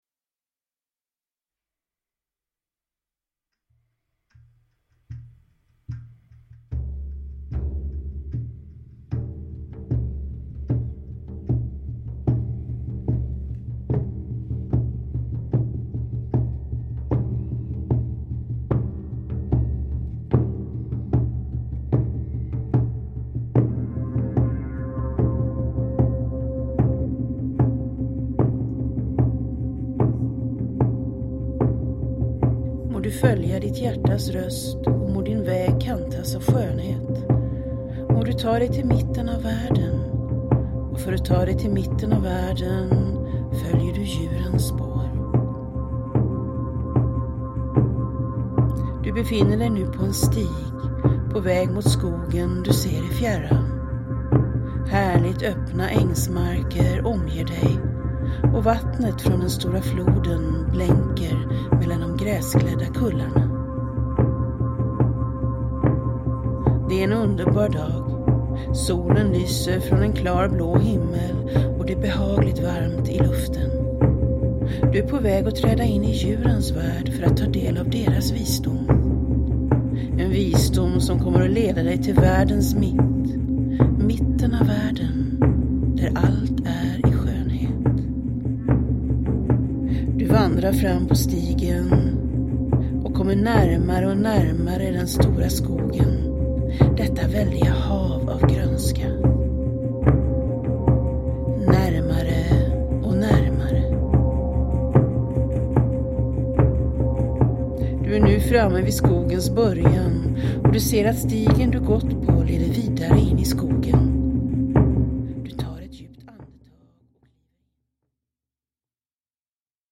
I djurens spår – Ljudbok – Laddas ner
I Djurens spår är en guidad resa till trummor